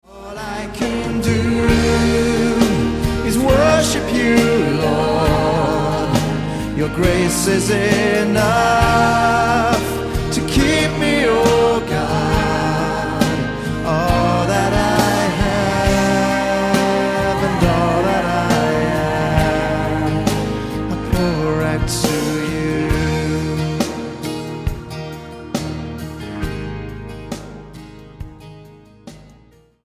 STYLE: Pop
warbling Hammond, a bright mix